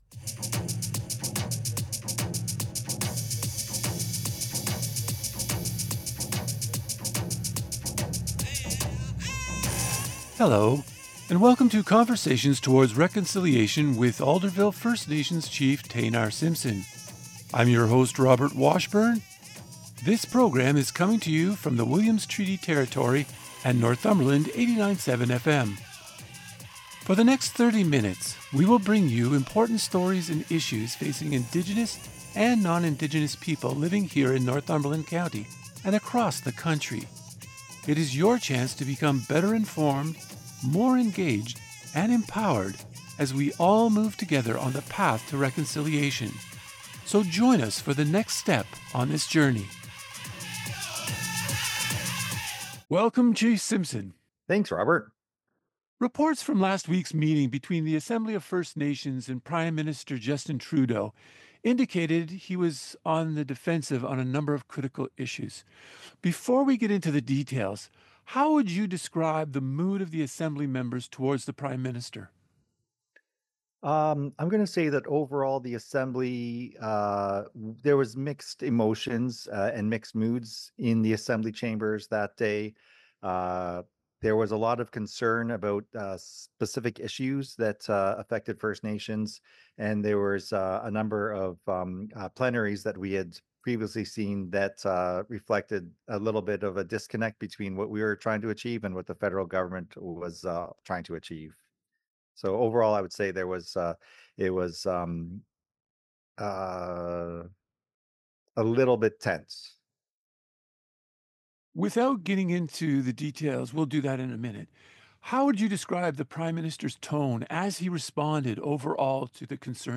On this month’s episode of Conversations Towards Reconciliation, Alderville Chief Taynar Simpson explores what happened and his reaction to the various politicians. The conversation also covers the importance of maintaining decorum in assemblies, the need for corporations to consult with Indigenous people before undertaking projects on their lands, and the announcement of several upcoming events and programs for the community. Lastly, the interview highlights the ongoing struggle for reconciliation and the need for further action to address unresolved issues.